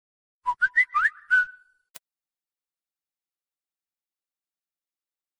• Качество: высокое
Стандартный звук уведомления WhatsApp на Android свист